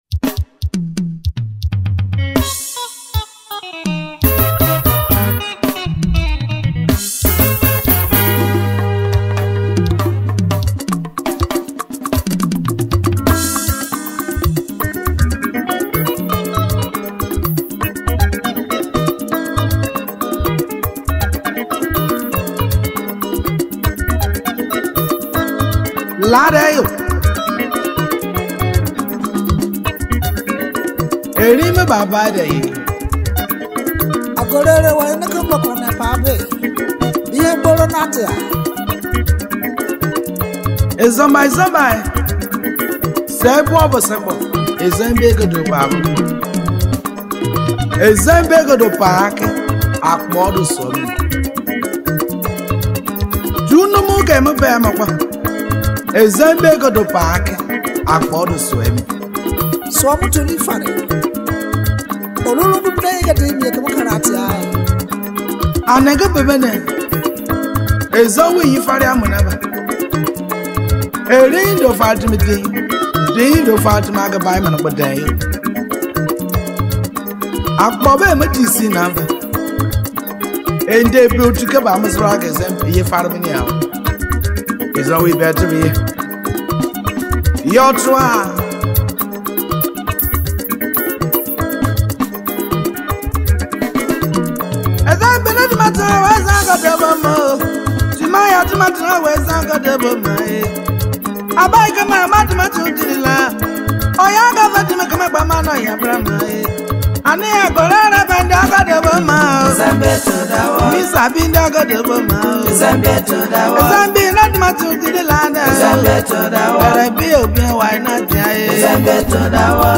Experience the rich sounds of Ijaw music
singer and songwriter